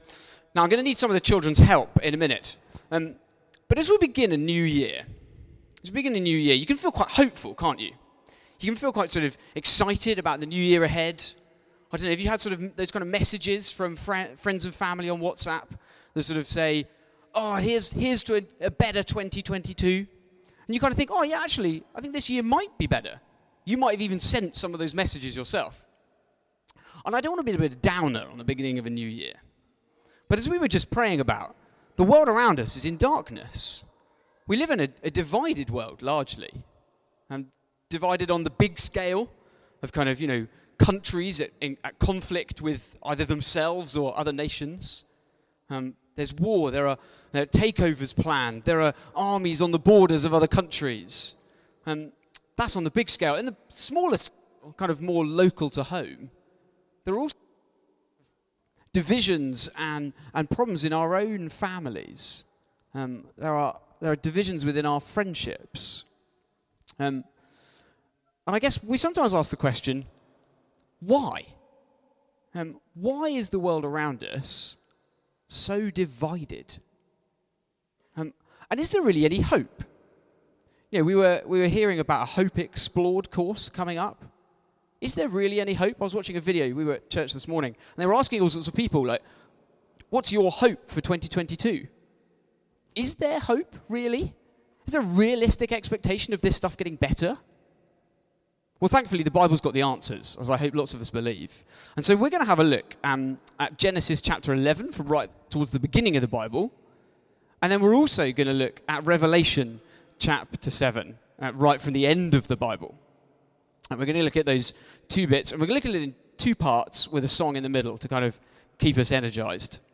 Genesis 11 Service Type: Weekly Service at 4pm « Christmas Carols